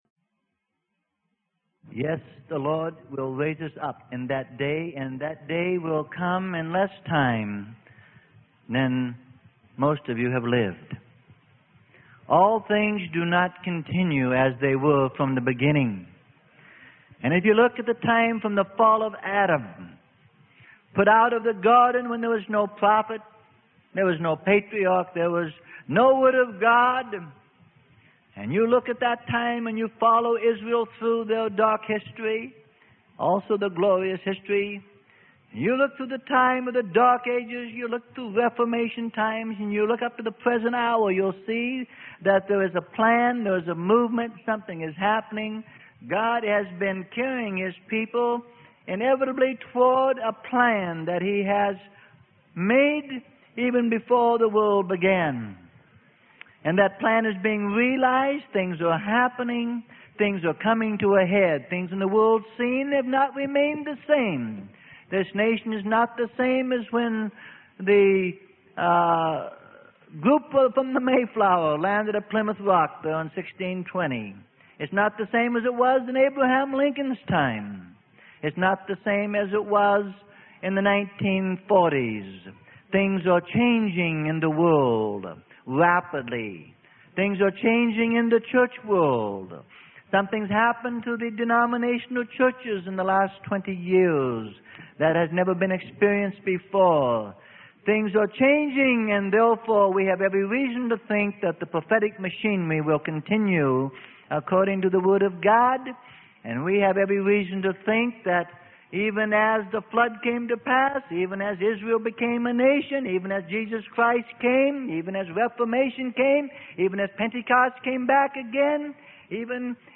Sermon: Examining the Armor of God - Part 2 - Freely Given Online Library